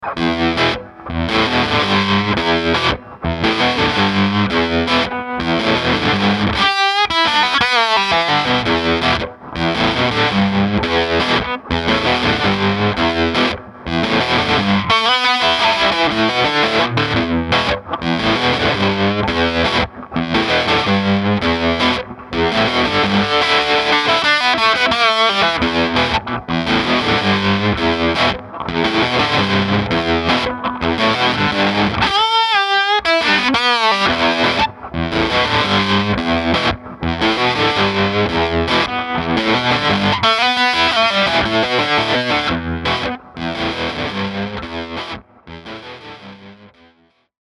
"‘Stoop' is my 2001 R9 Les Paul and ‘HRF' is a 1998 Howard Roberts Fusion. These two tracks are nothing but the amp and the guitars and a Shure 57.